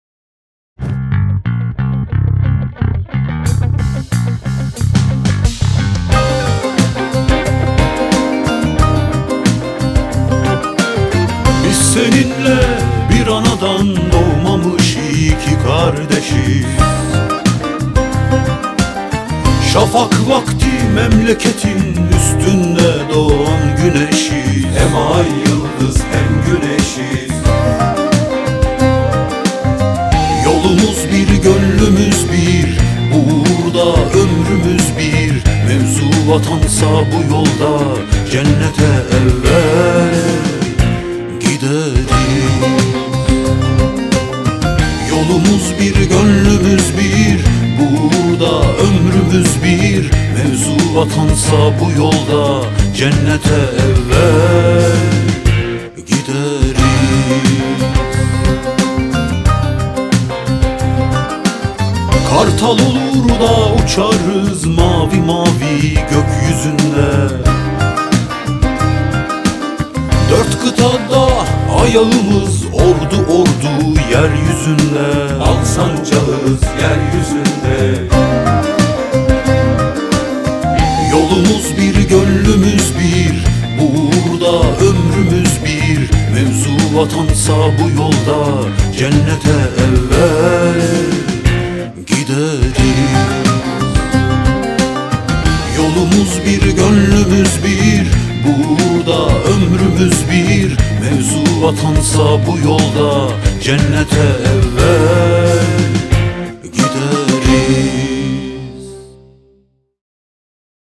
tema dizi müziği, duygusal heyecan enerjik fon müziği.